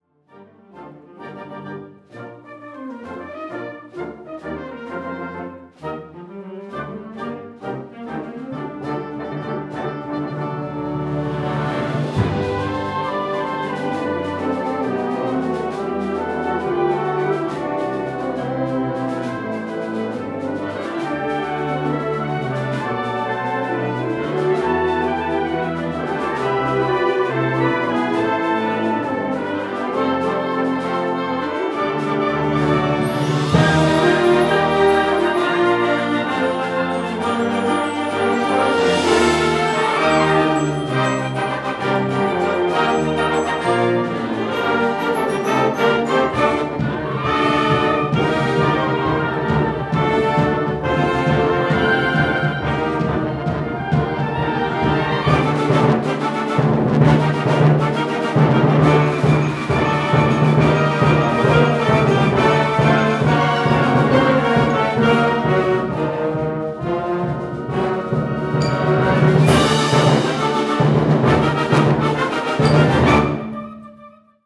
Frühlingskonzert 2025